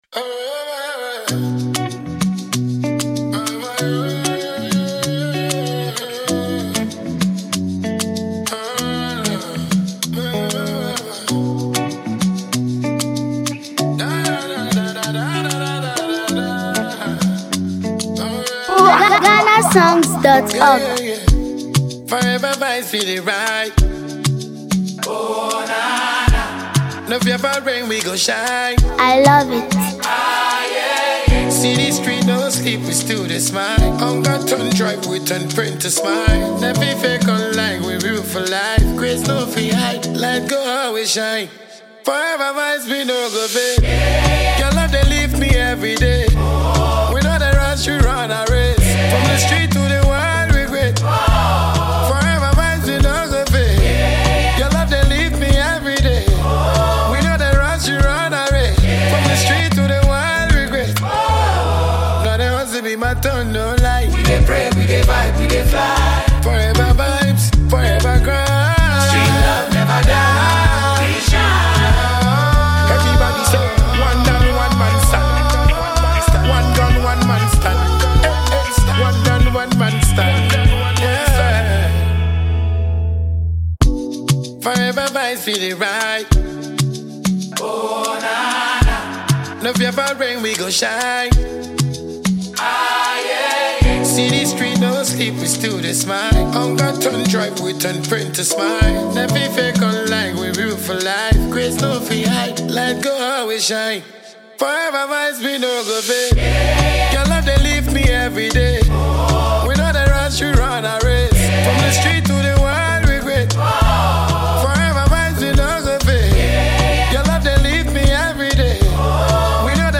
dancehall
smooth melodies and uplifting lyrics